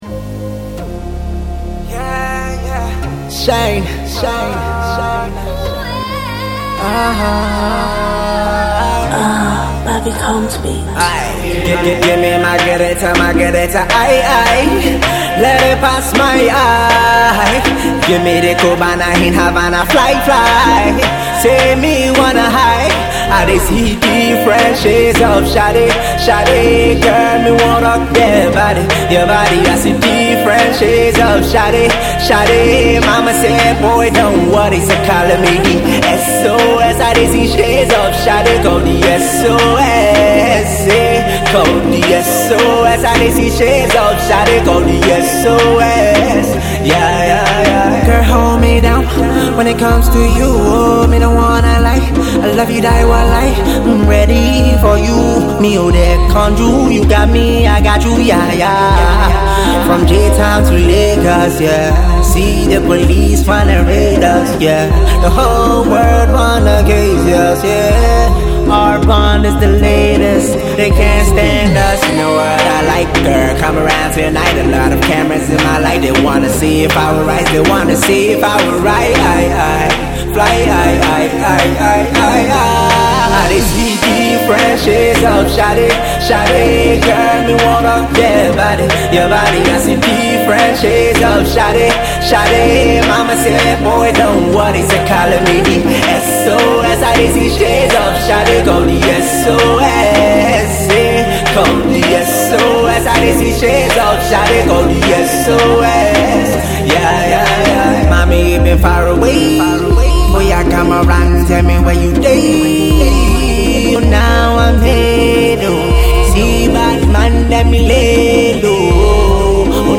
a fusion of Afro-Pop and R&B
a beautifully orchestrated melody